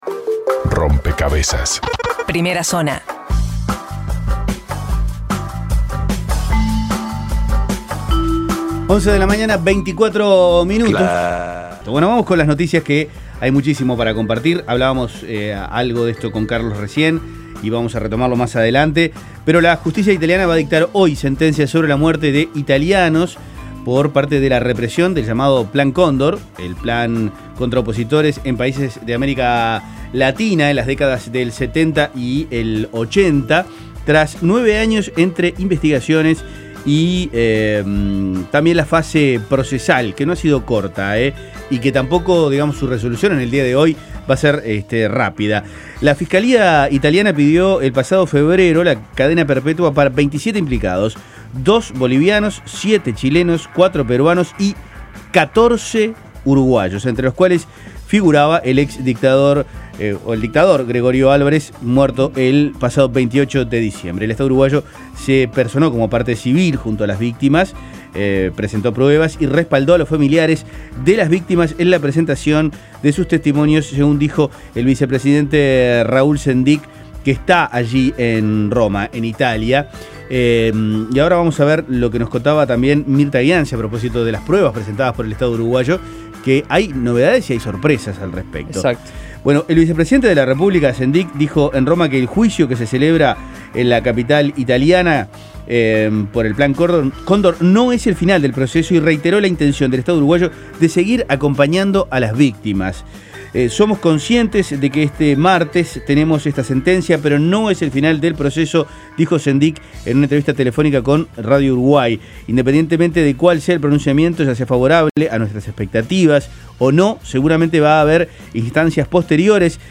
Las principales noticias del día, resumidas en la Primera Zona de Rompkbzas.